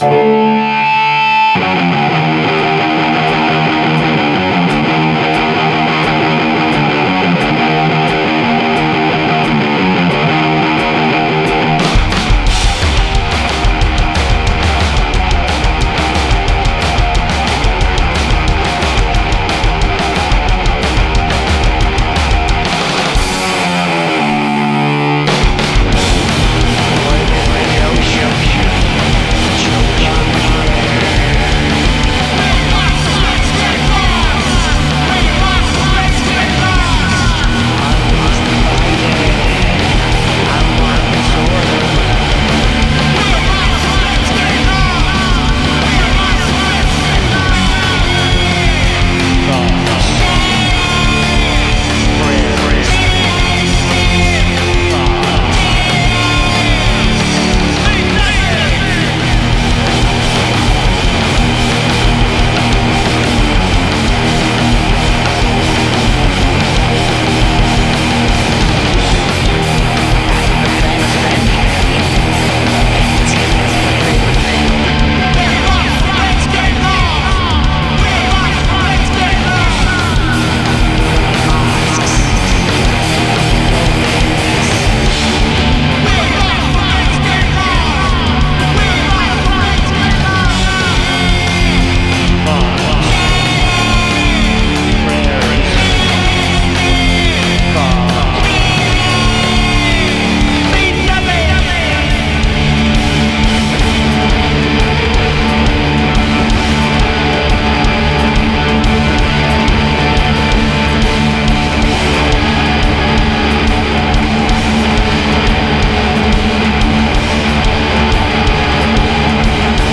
Recorded live
post-industrial supergroup